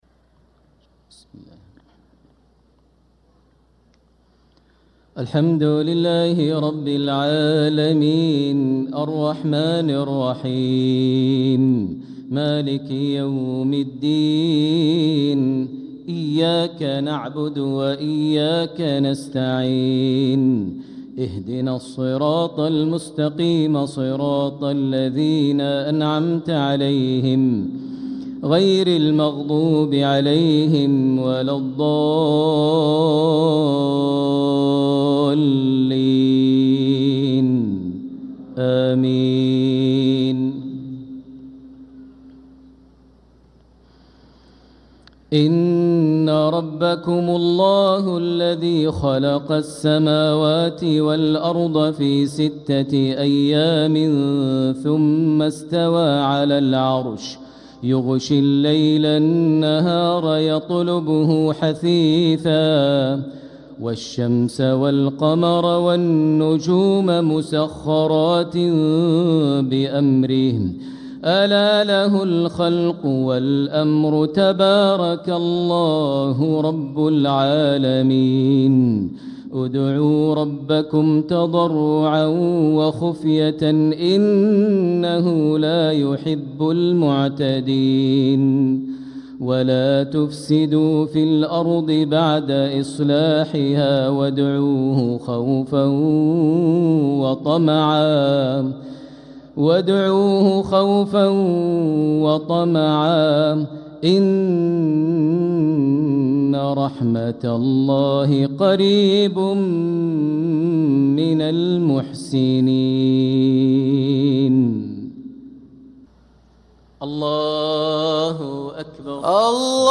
صلاة المغرب للقارئ ماهر المعيقلي 18 صفر 1446 هـ
تِلَاوَات الْحَرَمَيْن .